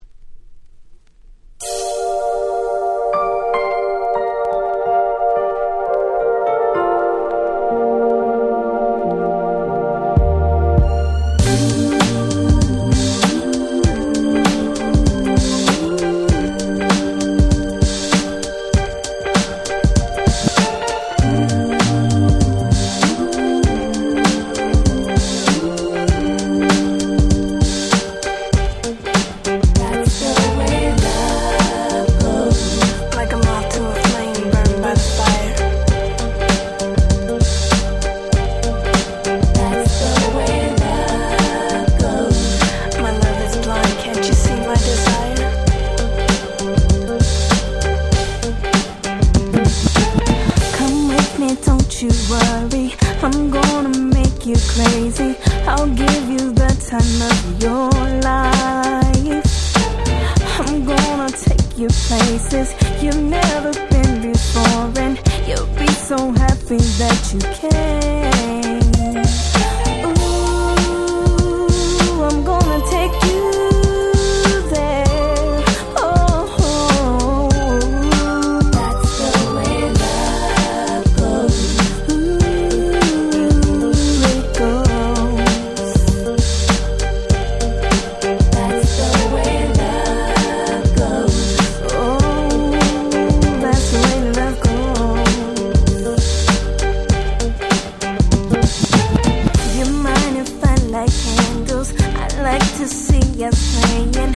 全編Hip HopとR&Bのみの非常にBlackな内容。